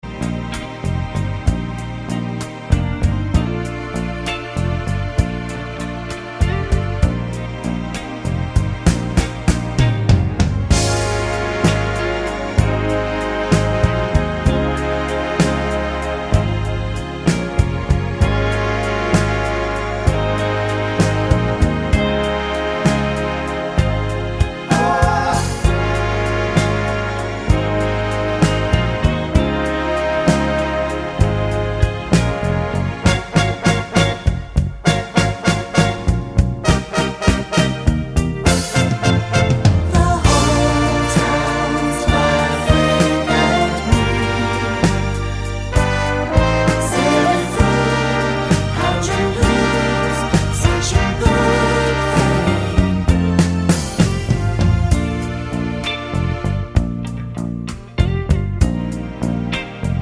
no Backing Vocals Soul / Motown 4:31 Buy £1.50